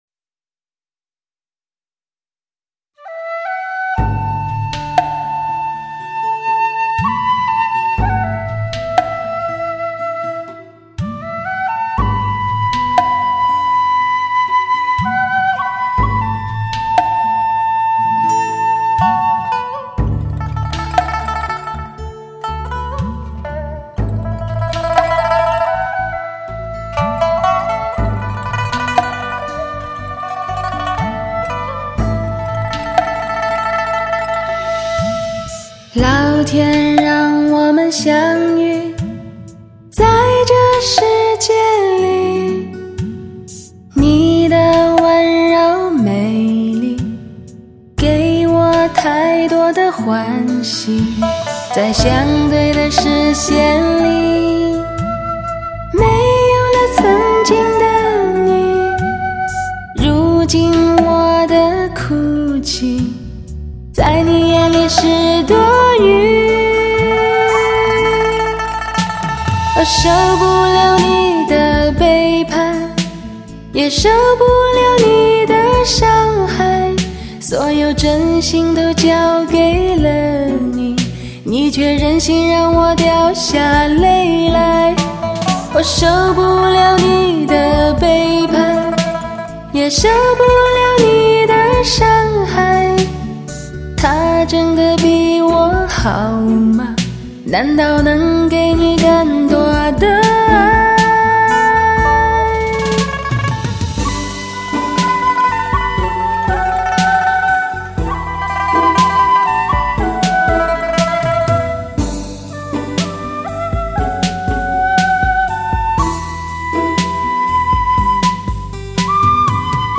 唱片类型：华语流行
发烧女声终极监听版